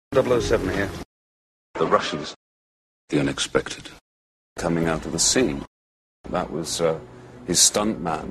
and something more like schwa (here both as Bond and in interviews):
dalton_mid_central.mp3